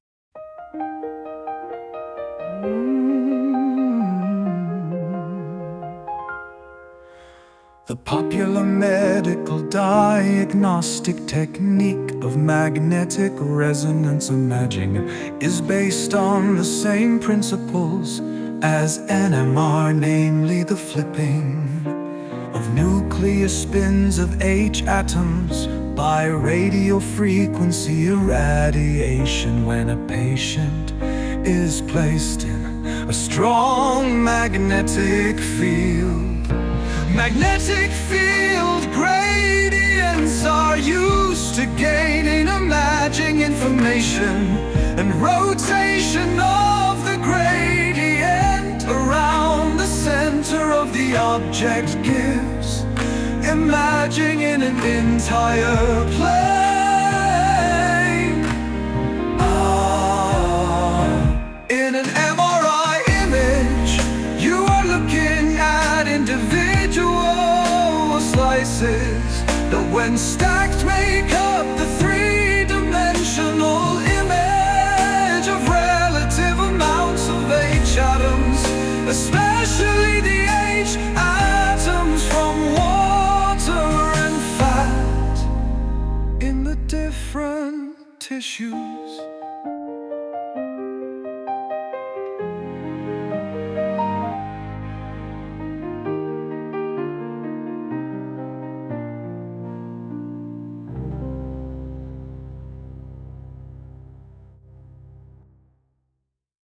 MRI paragraph as a country song